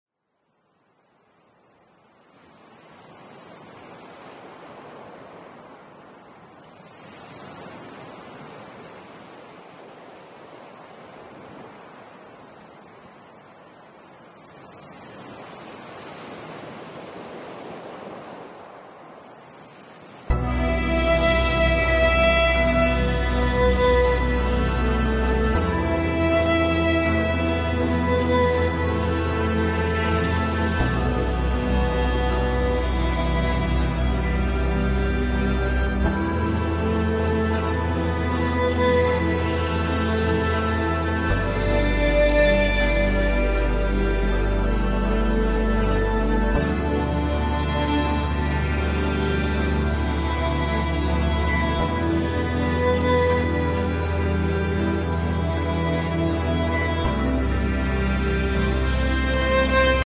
🏖 Tranquil Beach Waves | Soothing sound effects free download
🏖 Tranquil Beach Waves | Soothing Ocean Sounds for Deep Relaxation 🌊 Let the gentle waves and calming breeze melt your stress away.